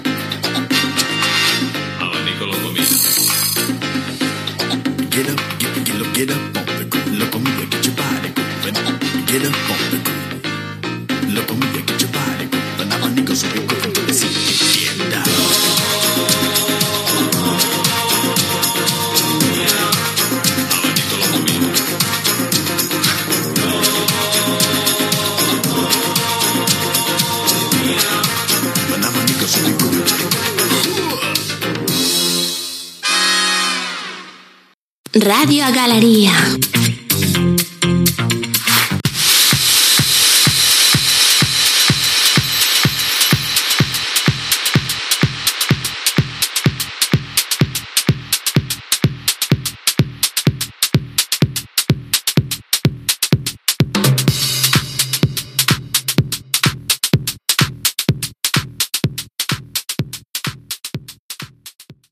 Tema musical i indicatiu de l'emissora